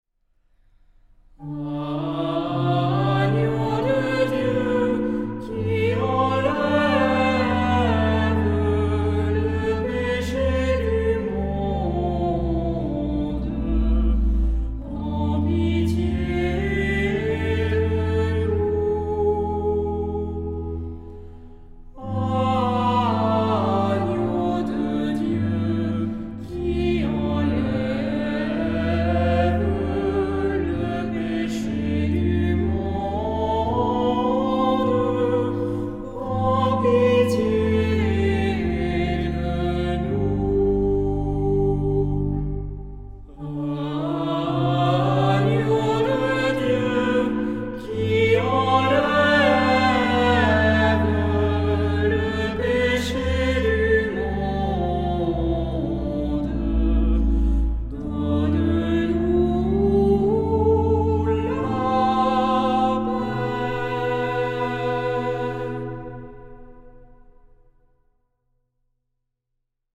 Tonalité : ré (centré autour de)